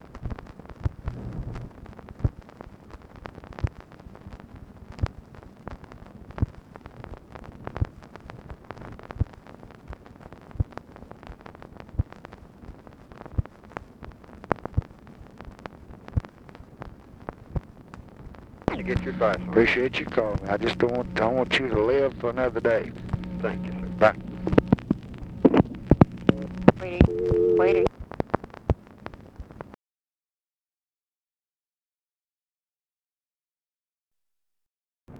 Conversation with BIRCH BAYH, June 23, 1966
Secret White House Tapes